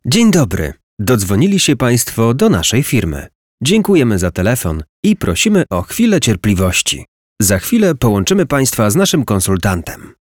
Lektor do zapowiedzi telefonicznych
Przykład lektorskiej zapowiedzi powitania telefonicznego:
ZapowiedziTelefoniczne_powitanie_2025.mp3